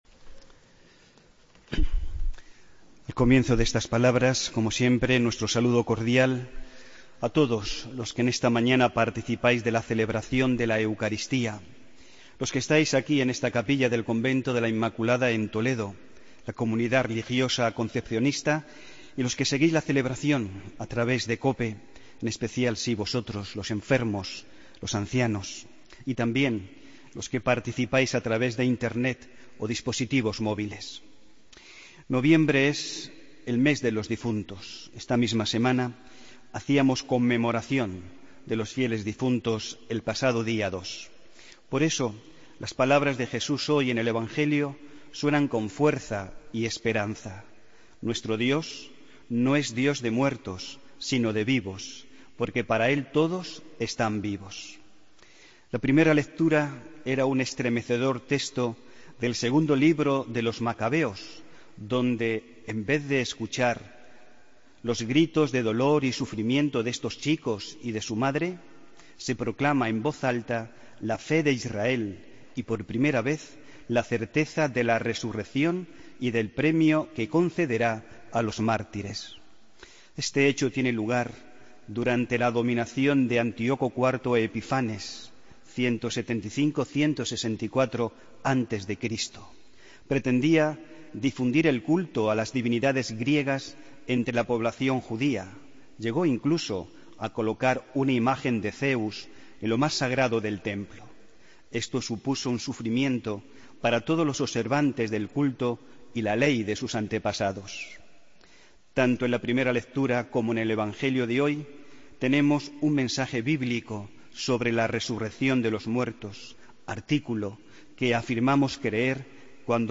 Homilía del domingo 6 de noviembre de 2016